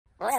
"Red Bird Laughing" Sound Variations